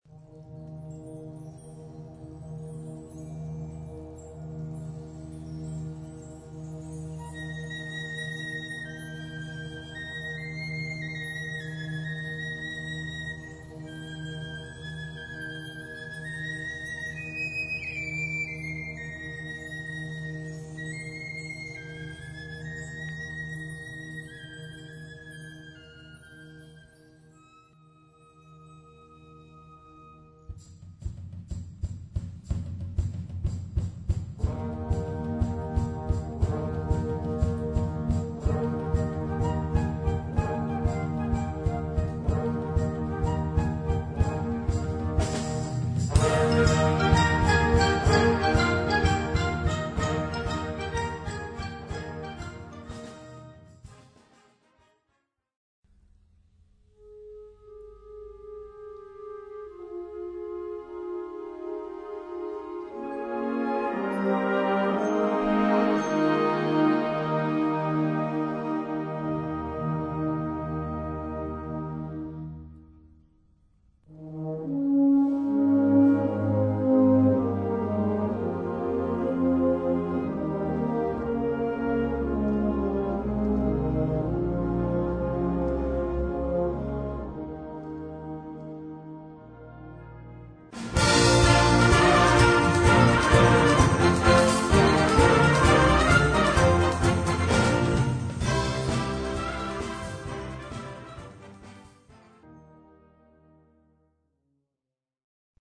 Gattung: Solo für diverse Instumente und Blasorchester
Besetzung: Blasorchester